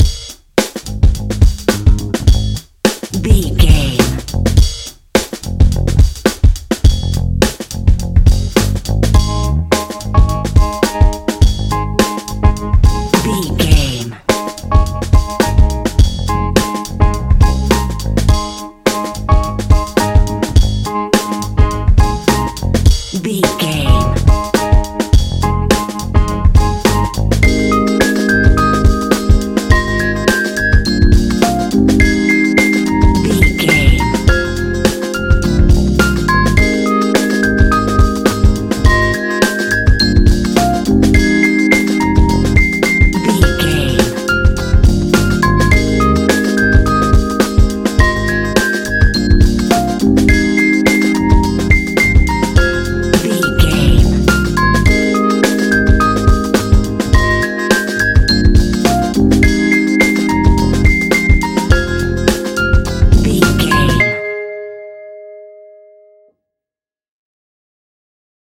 Ionian/Major
G♯
chilled
laid back
Lounge
sparse
new age
chilled electronica
ambient
atmospheric
morphing